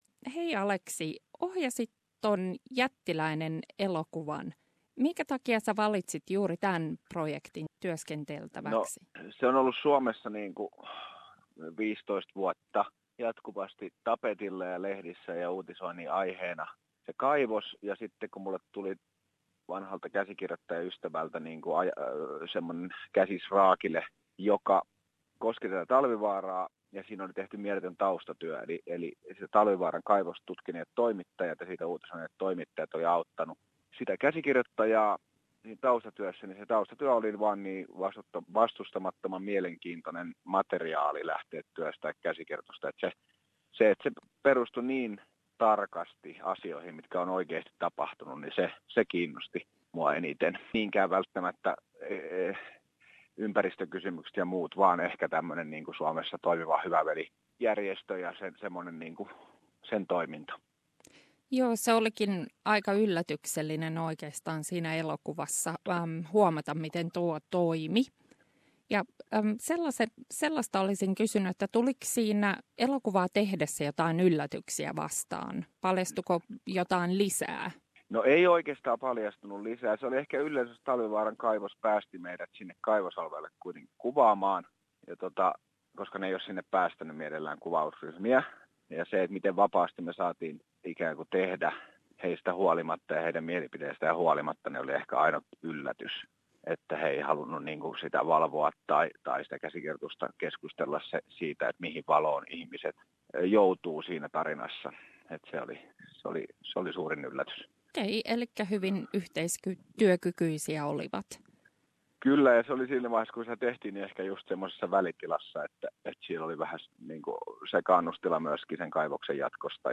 Elokuvaohjaaja Aleksi Salmenperän haastattelu